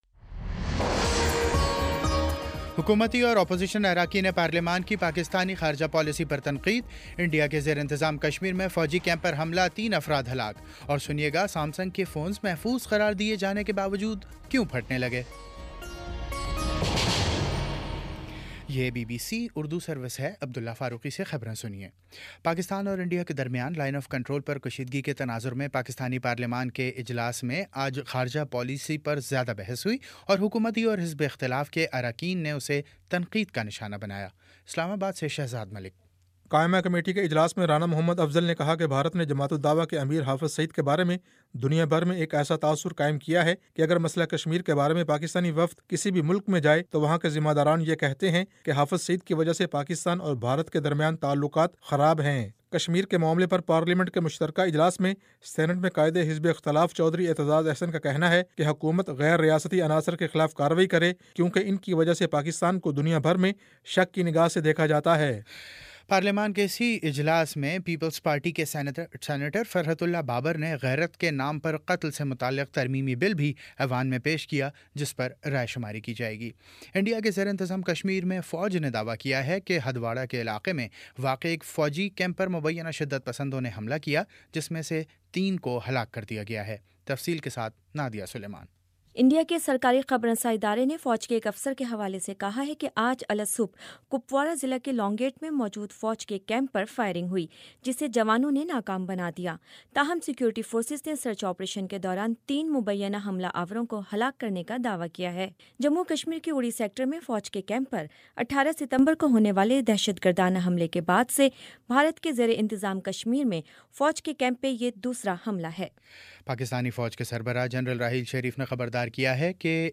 اکتوبر 06 : شام پانچ بجے کا نیوز بُلیٹن